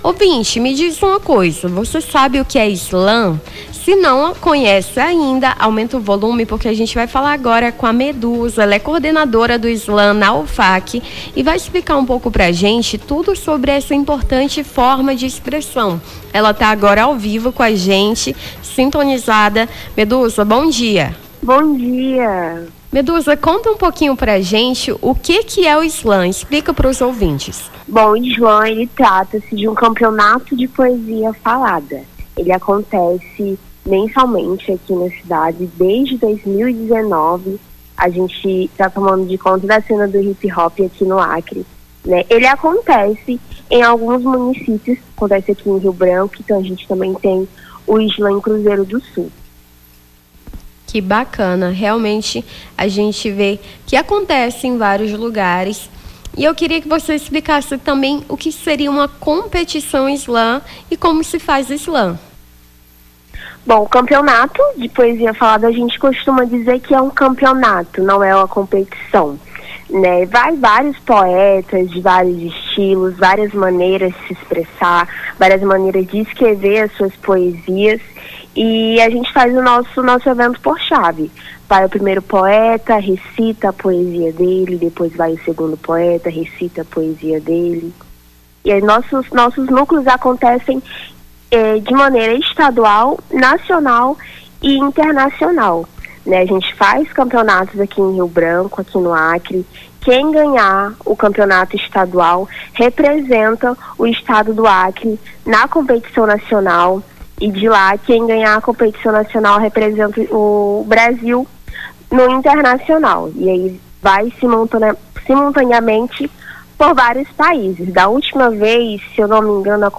Nome do Artista - CENSURA - ENTREVISTA SLAM NA UFAC (13-03-25).mp3